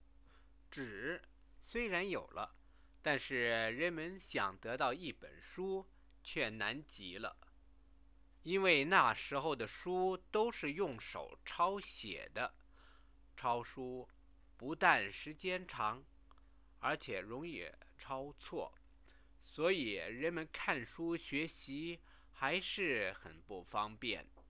Click "Listen" to hear the sentences spoken Questions (Place the mouse on the questions to view the answers)